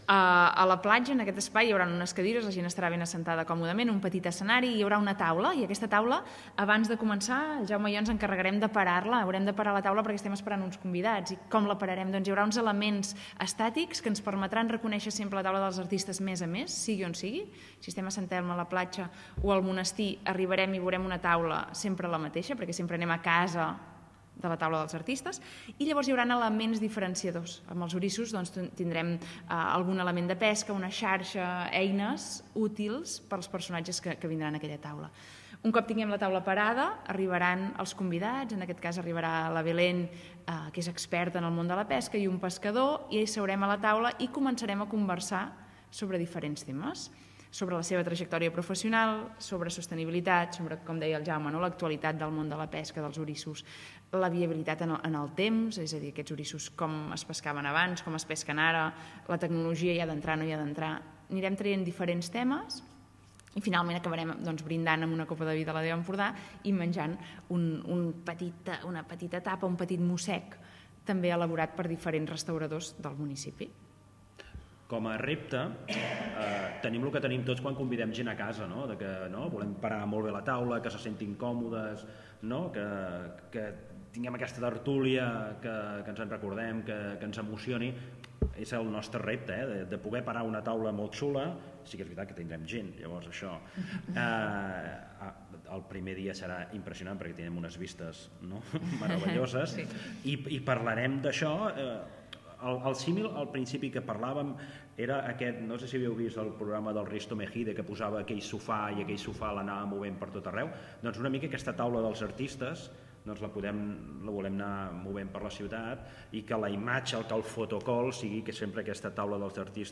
Tots dos explicaven el funcionament de la jornada.